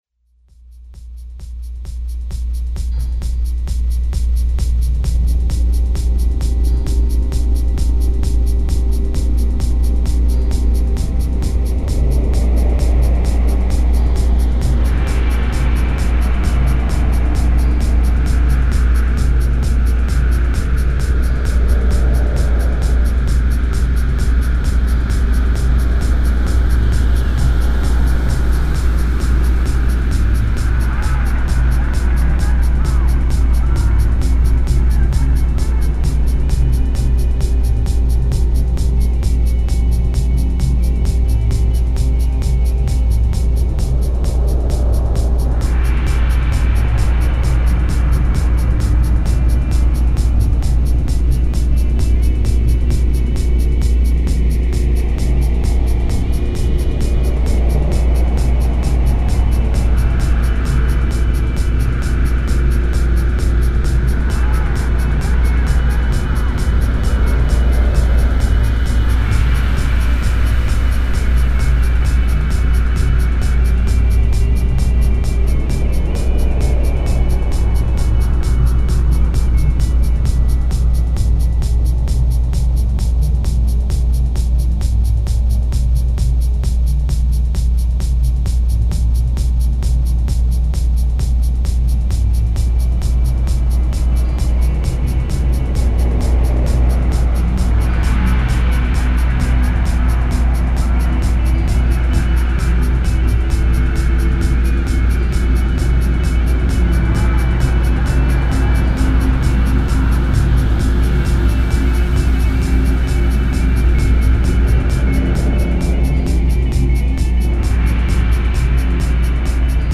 Techno Ambient